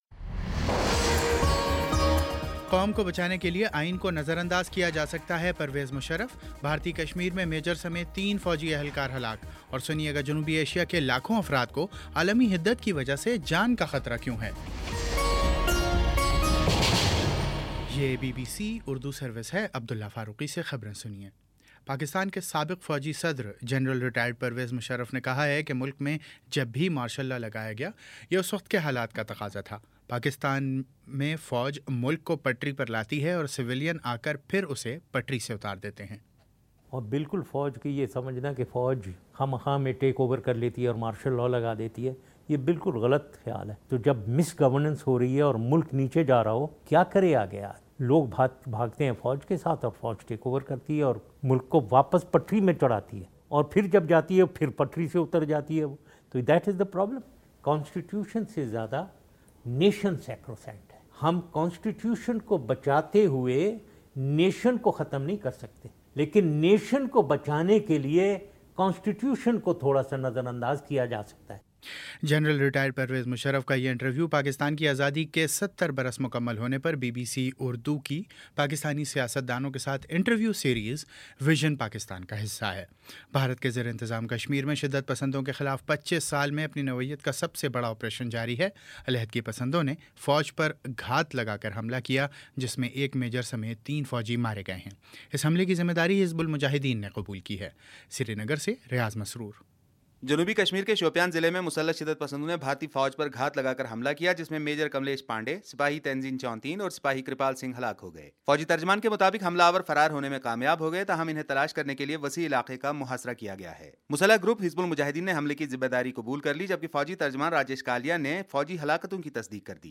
اگست 03 : شام چھ بجے کا نیوز بُلیٹن